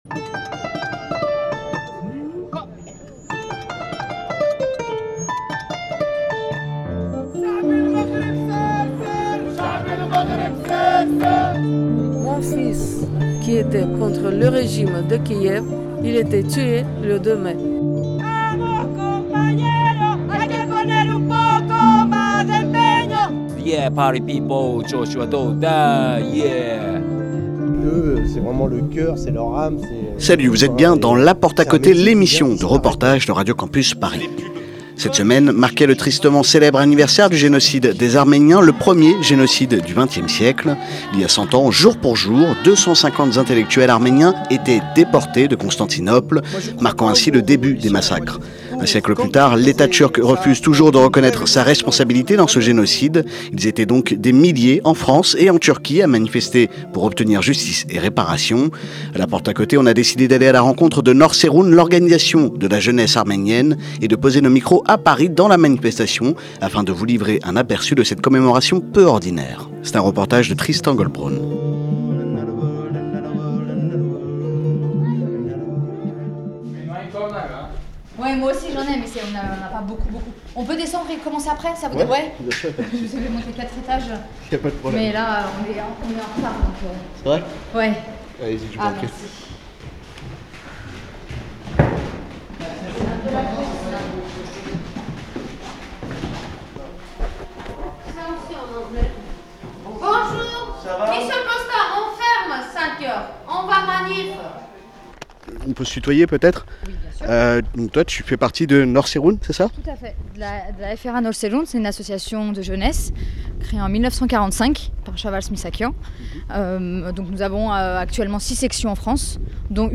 Manifestation pour le centenaire du génocide des Arméniens.
Cette semaine, La porte à côté vous emmène au cœur de la manifestation pour le centenaire du génocide des Arméniens à Paris.